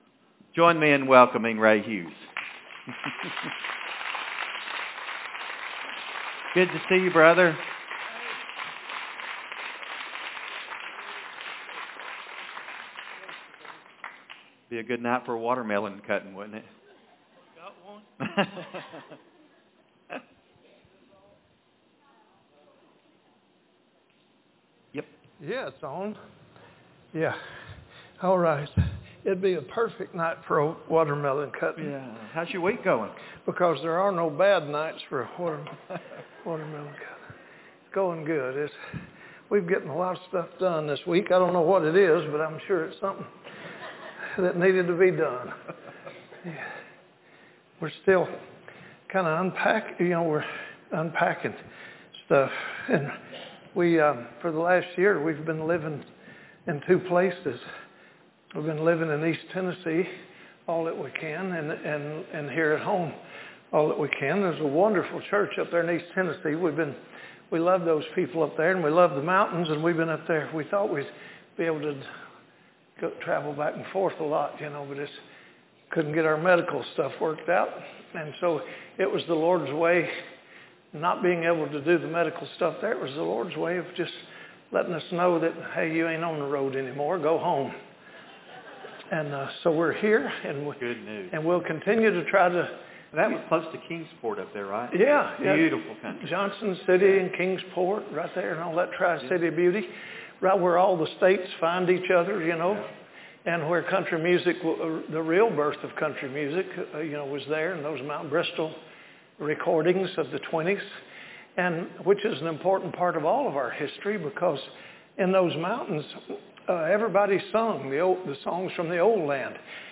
Current Sermon
Guest Speaker